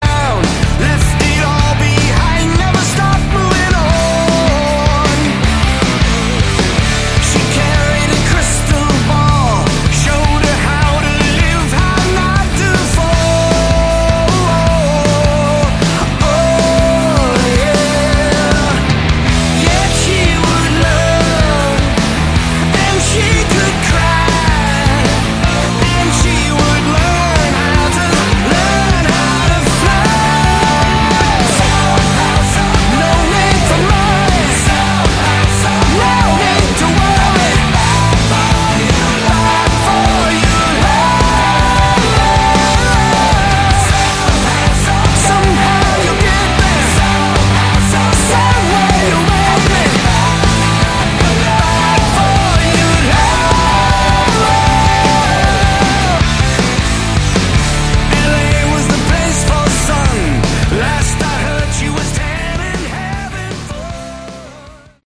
Category: Melodic Hard Rock
drums, percussion, lead and backing vocals
keyboards, piano, backing vocals